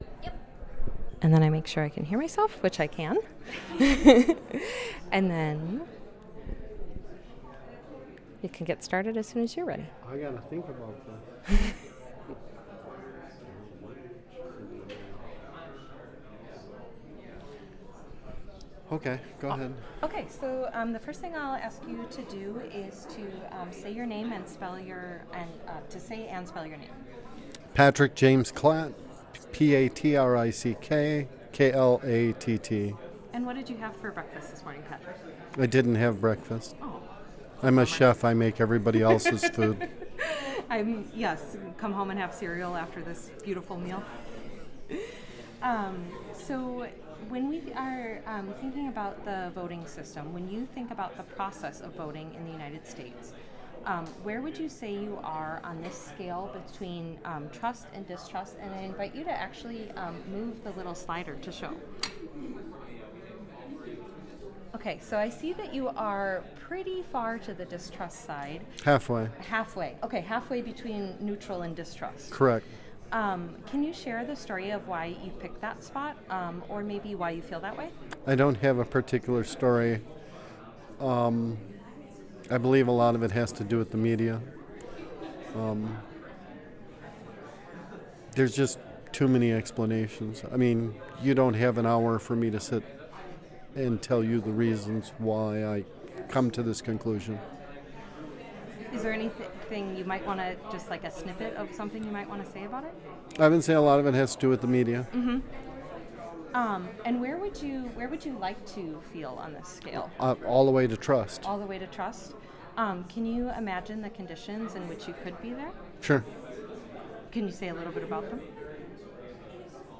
Location UWM at Waukesha